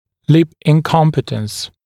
[lɪp ɪn’kɔmpɪtəns][лип ин’компитэнс]несмыкаемость губ